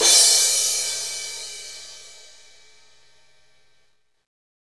Index of /90_sSampleCDs/Northstar - Drumscapes Roland/CYM_Cymbals 2/CYM_F_T Cyms x